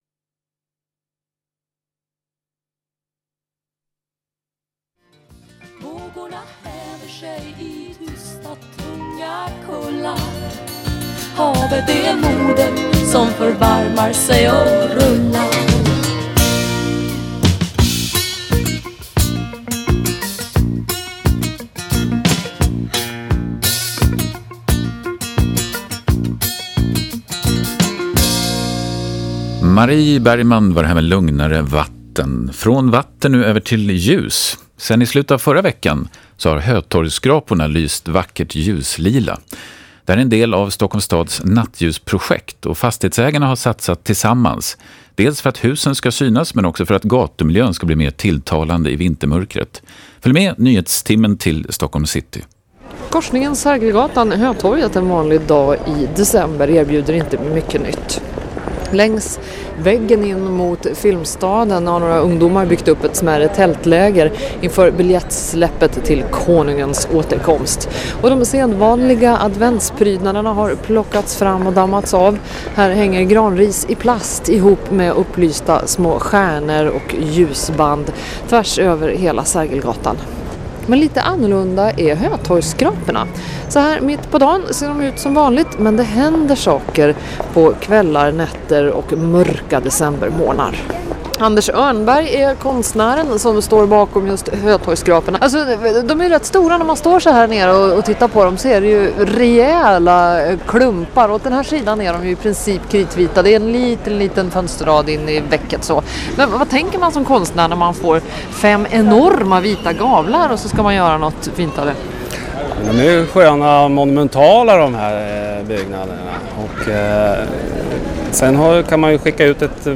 Sveriges Radio intervju